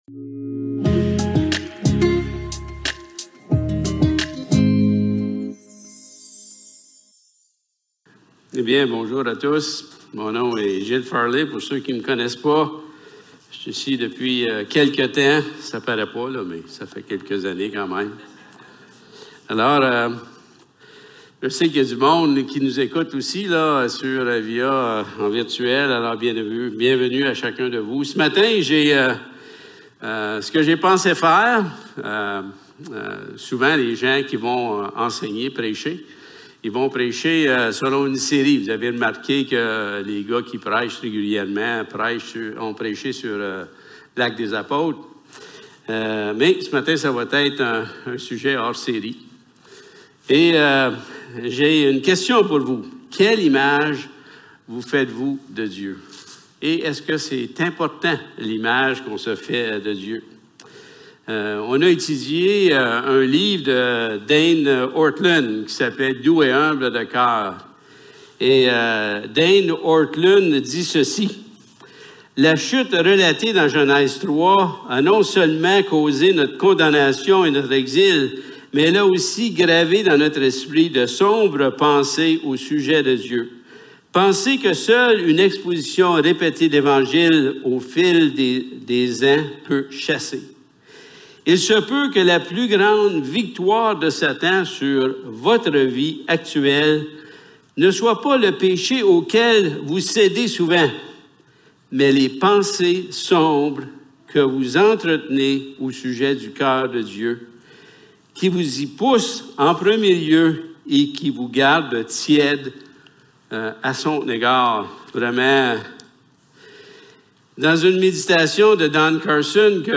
Psaumes 139 Service Type: Célébration dimanche matin Il va s’en dire que les pensées que nous avons de Dieu influence grandement la relation que j’ai que tu as avec Dieu!